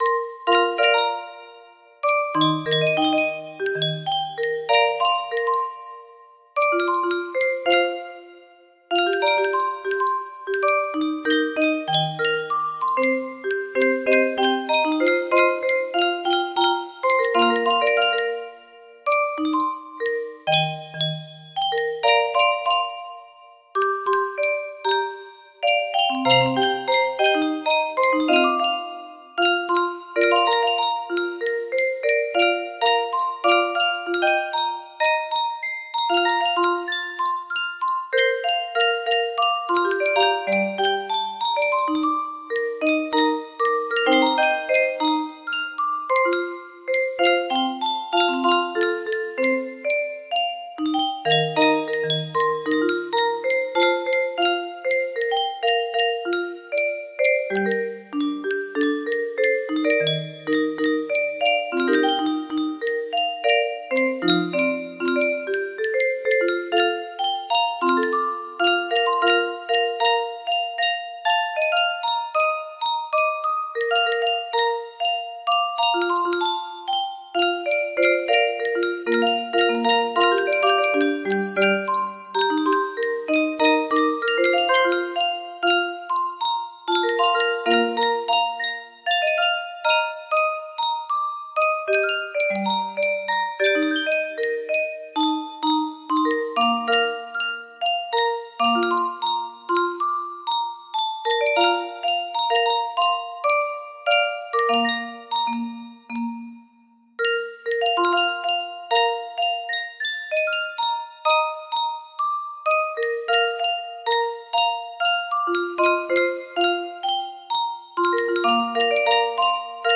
Silent Whispers music box melody
Full range 60